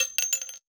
weapon_ammo_drop_06.wav